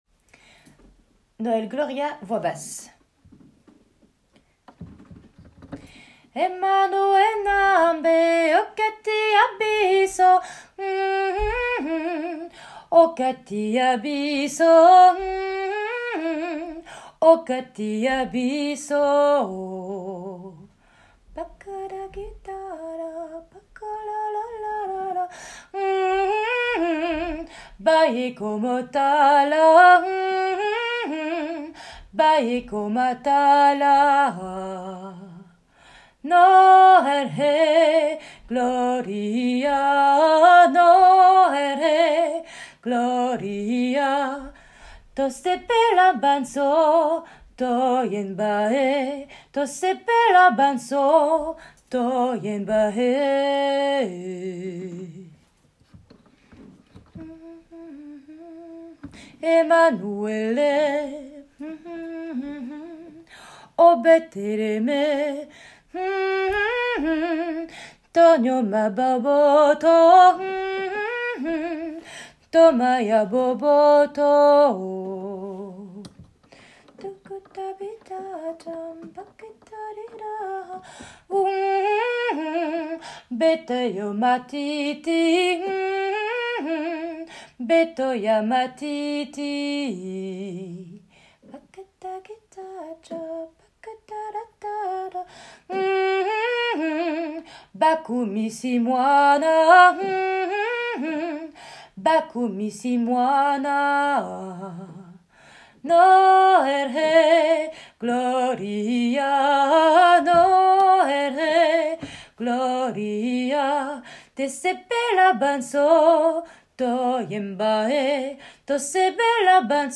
Basse
No--l-Gloria---Basse.m4a